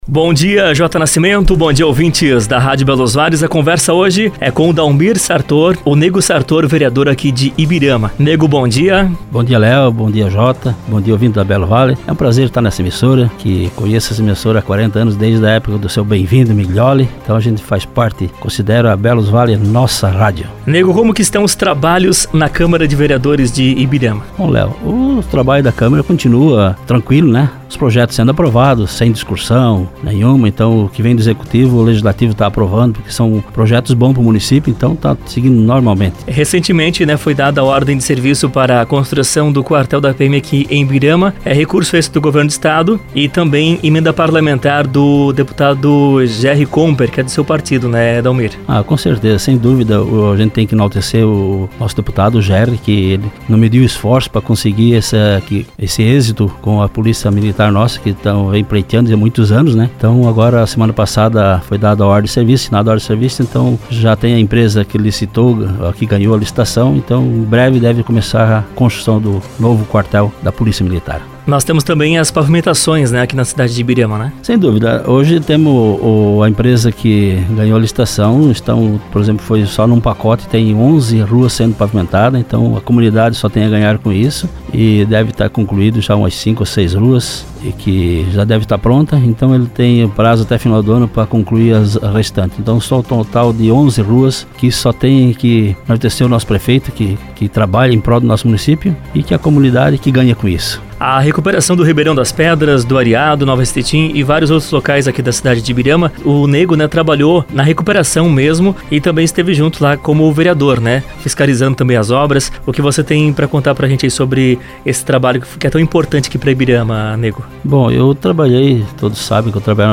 Entrevista com Dalmir Sartor Vereador do MDB de Ibirama